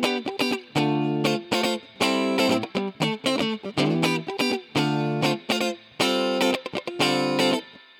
23 Guitar PT2.wav